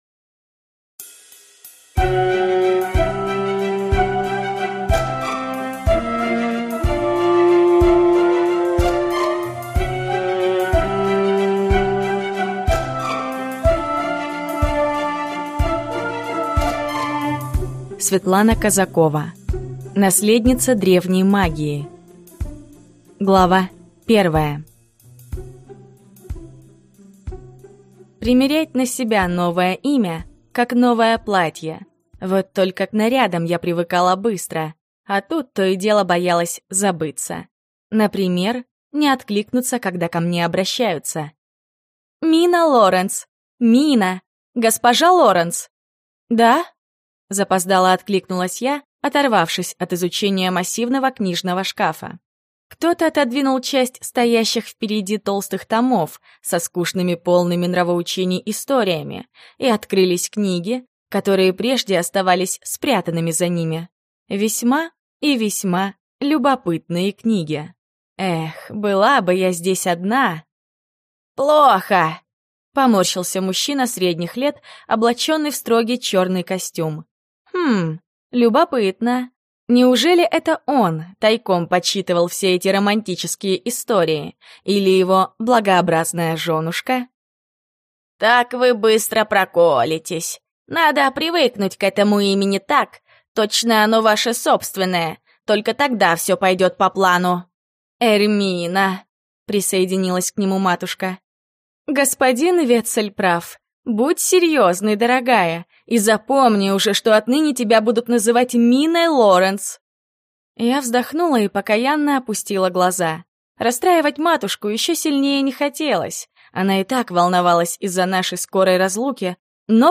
Аудиокнига Наследница древней магии | Библиотека аудиокниг